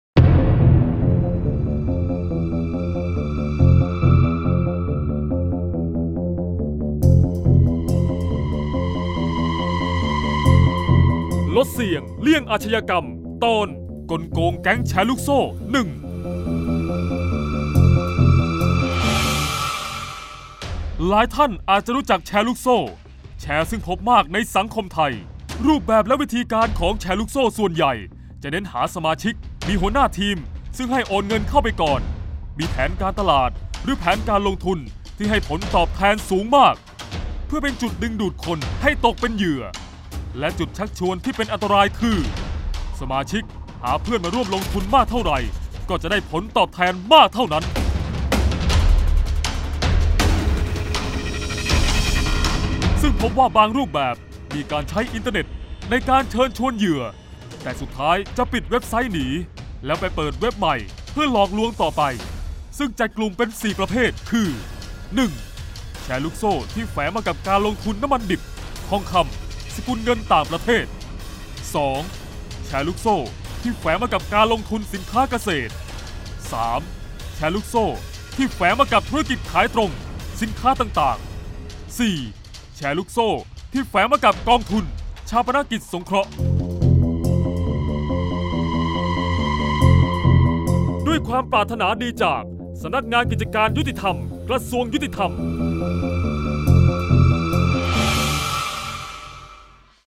เสียงบรรยาย ลดเสี่ยงเลี่ยงอาชญากรรม 09-กลโกงแชร์ลูกโซ่-1